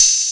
Open Hats
OPENHAT (TRIP)(1).wav